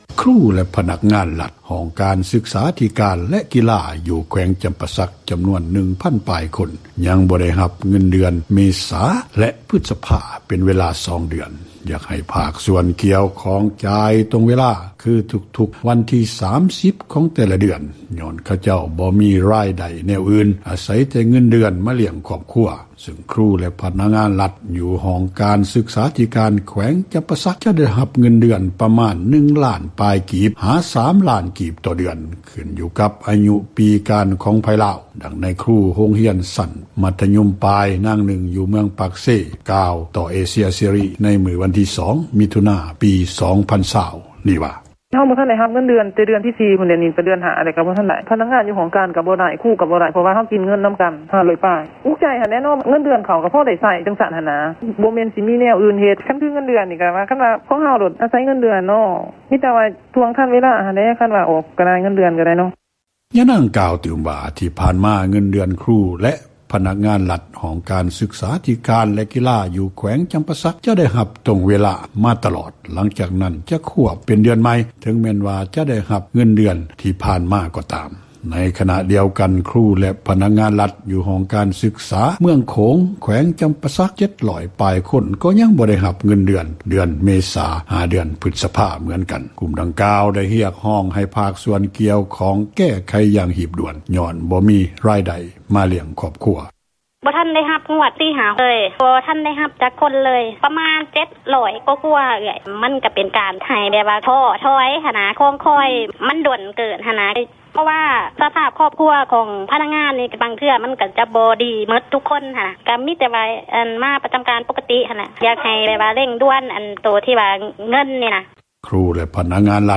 ນັກຂ່າວພົລເມືອງ
ຄຣູແລະພະນັກງານຣັຖ ຫ້ອງການສຶກສາທິການ ແລະກິລາ ຢູ່ແຂວງຈຳປາສັກ ຈຳນວນ 1 ພັນປາຍຄົນ ຍັງບໍ່ໄດ້ຮັບເງິນເດືອນເມສາ ແລະ ພຶສພາ ເປັນເວລາສອງເດືອນ ຢາກໃຫ້ພາກສ່ວນກ່ຽວຂ້ອງ ຈ່າຍຕົງ ເວລາຄືທຸກໆວັນທີ 30 ຂອງແຕ່ລະເດືອນ ຍ້ອນຂະເຈົ້າບໍ່ຣາຍໄດ້ ແນວອື່ນ ອາສັຍແຕ່ເງິນເດືອນ ມາລ້ຽງຄອບຄົວ ຊື່ງຄຣູແລະພະນັກງານຣັຖ ຢູ່ຫ້ອງການສຶກສາ ແລະກິລາ ແຂວງຈຳປາສັກ ໄດ້ຮັບເງິນ ເດືອນ ປະມານ 1 ລ້ານ ປາຍກີບ ຫາ 3 ລ້ານປາຍກີບ ຕໍ່ເດືອນຂຶ້ນຢູ່ກັບອາຍຸປີການ ຂອງໃຜລາວ, ດັ່ງນາຍຄຣູ ໂຮງຮຽນຊັ້ນມັທຍົມປາຍ ນາງນຶ່ງຢູ່ ເມືອງປາກເຊ ກ່າວໃນມື້ວັນທີ 2 ມິຖຸນາ ປີ 2020 ນີ້ວ່າ: